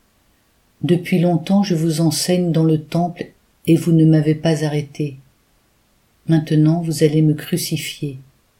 Antienne-Psaume-118-14-Depuis-longtemps-je-vous-enseigne.mp3